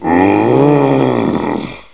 PSP/CTR: Also make weapon and zombie sounds 8bit